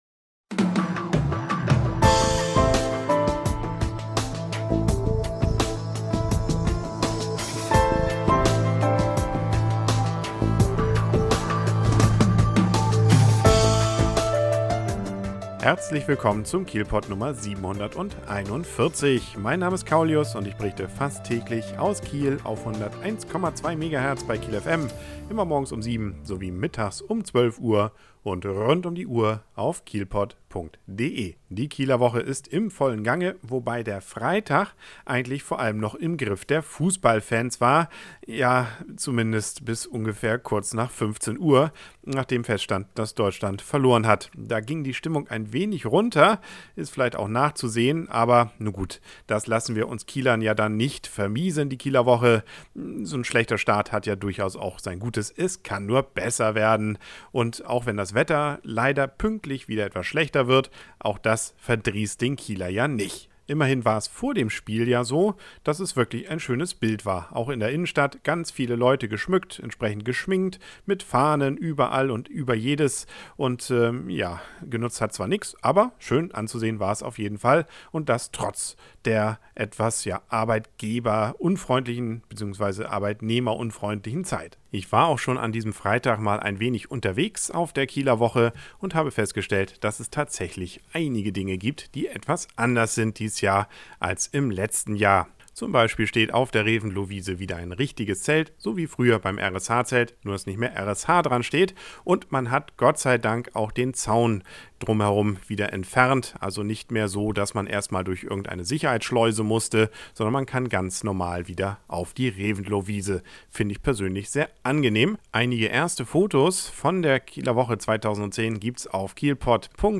Public Viewing in der Innenstadt, Bericht vom ersten Kieler-Woche-Freitag (inkl. Interview direkt vom Crepes-Stand) und Vorschau auf das Programm am Samstag.